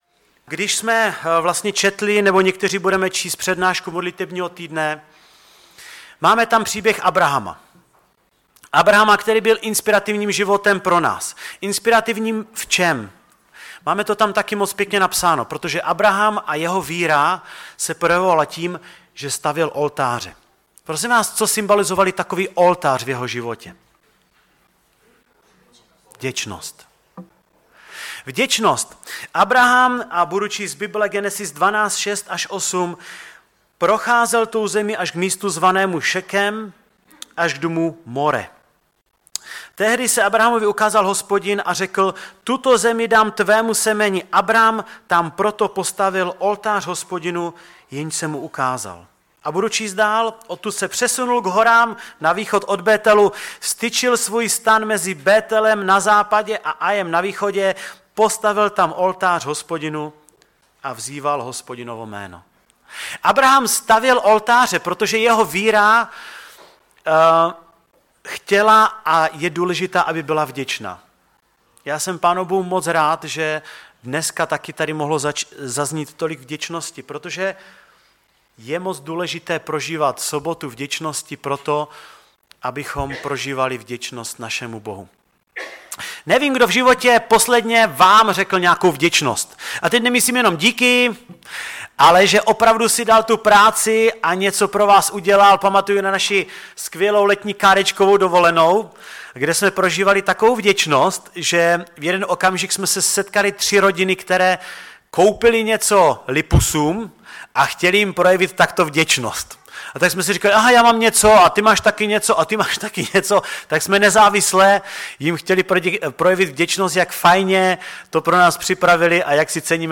ve sboře Ostrava-Radvanice.